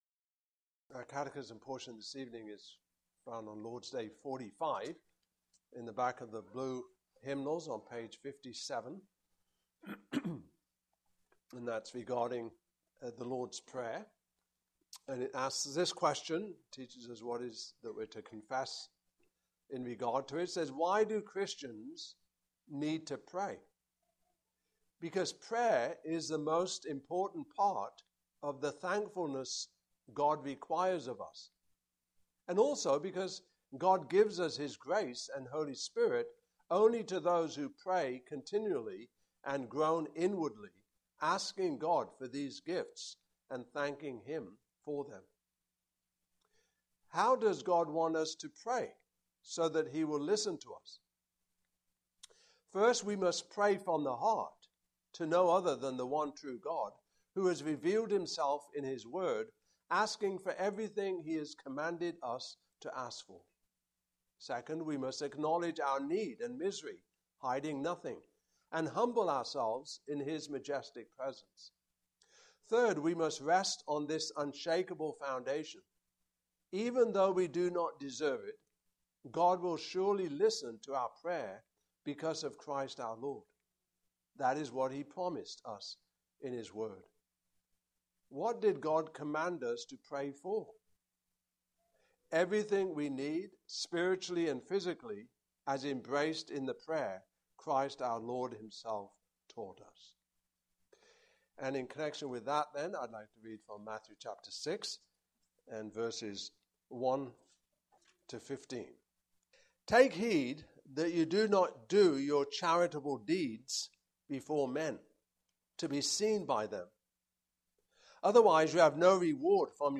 Passage: Matthew 6:1-15 Service Type: Evening Service